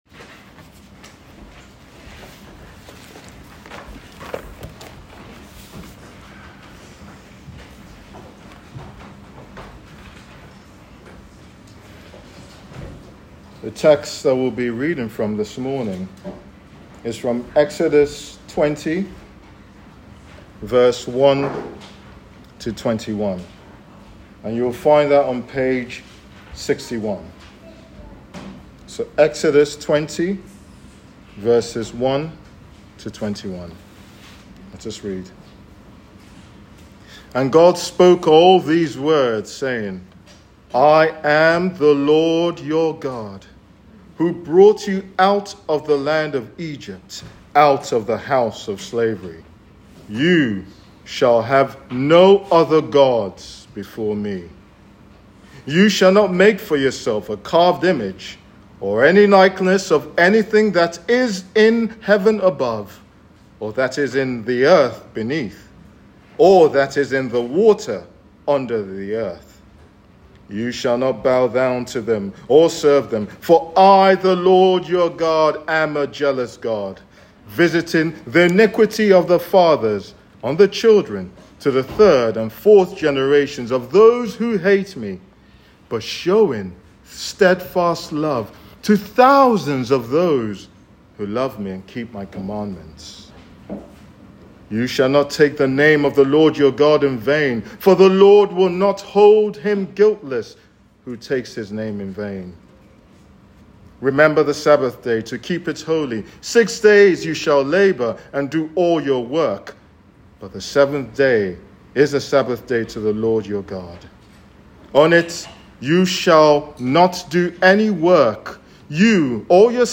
All Sermons “Ten Commandments